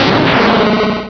pokeemerald / sound / direct_sound_samples / cries / tyranitar.aif
-Replaced the Gen. 1 to 3 cries with BW2 rips.